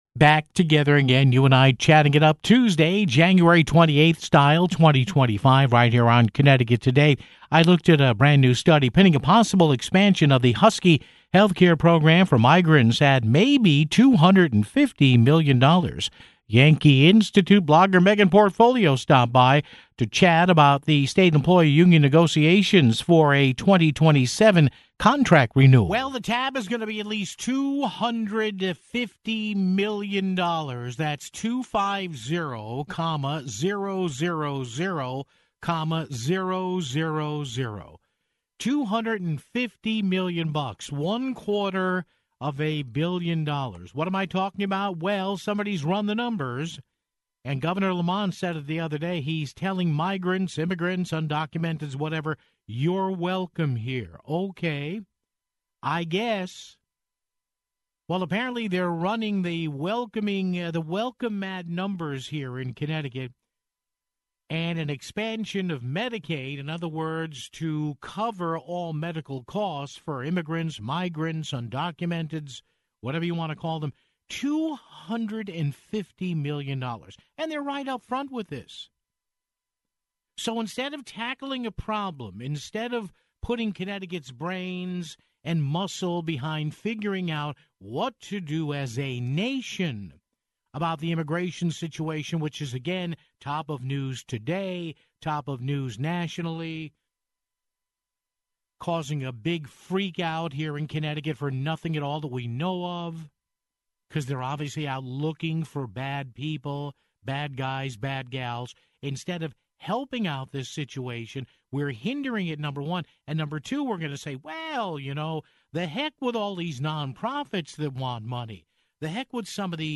stopped by to chat about state employee union negotiations for a 2027 contract renewal (14:45)